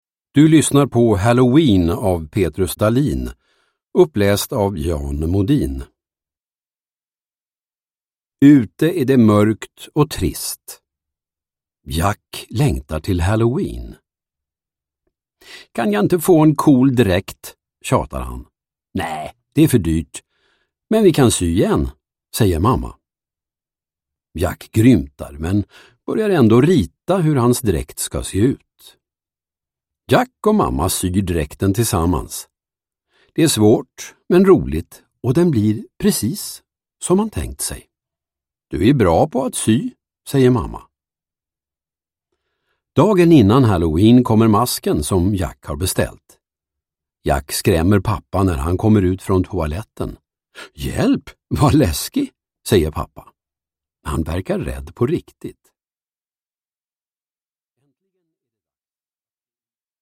Halloween – Ljudbok